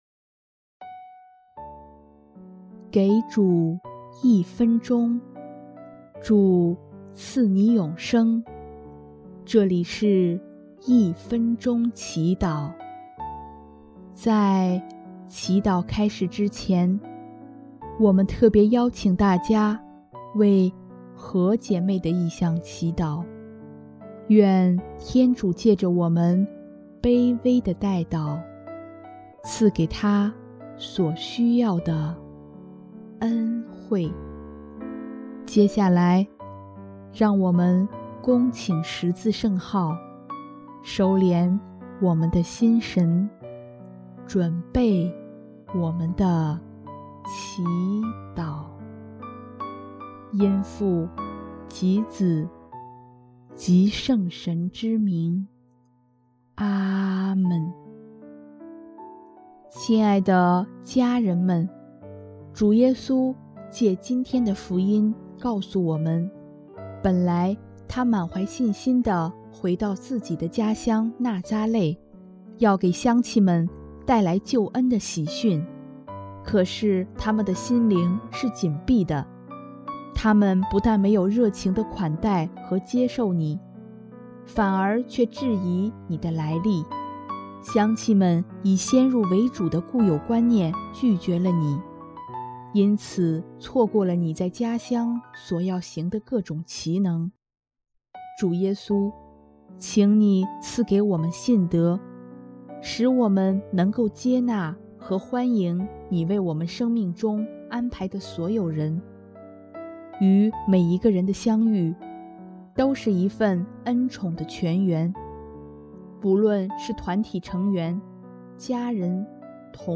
【一分钟祈祷】|5月1日 让我们在他人身上认出祢来